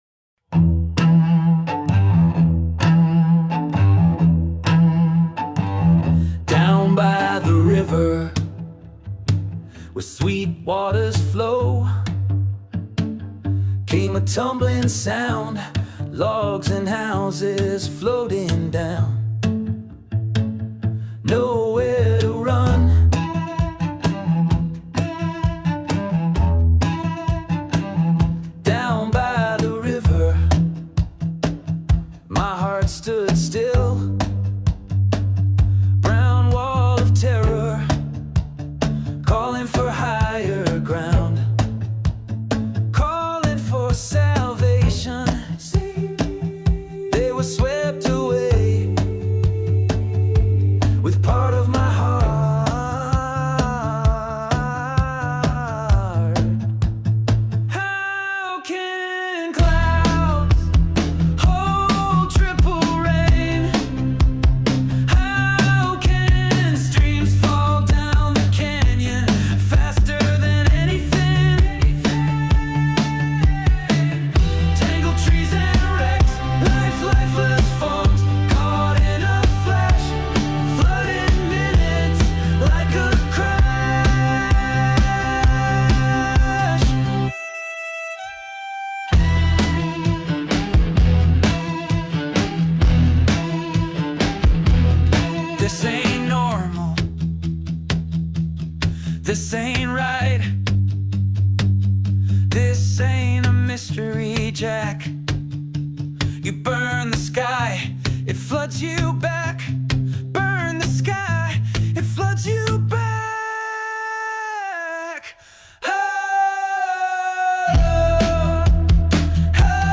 AI music
or this Rock version (my preference) …
AI_DownByTheRiver_Rock.mp3